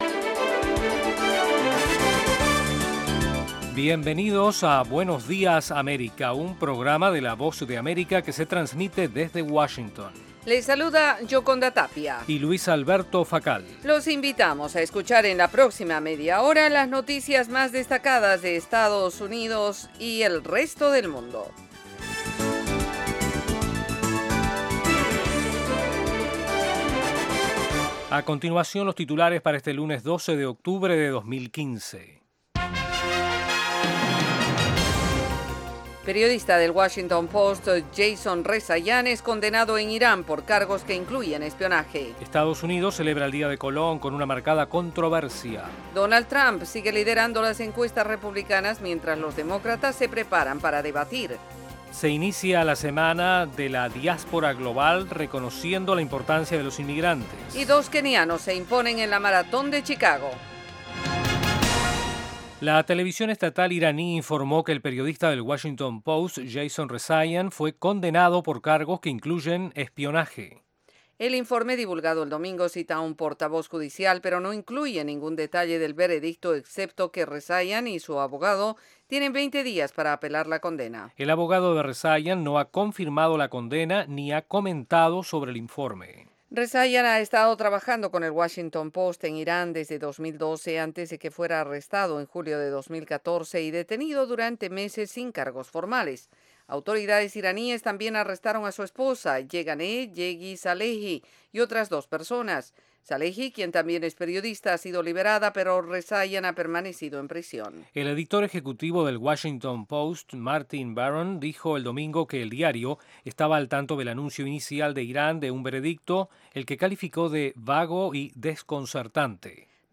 Información ágil y actualizada en las voces de los protagonistas con todo lo que sucede en el mundo, los deportes y el entretenimiento.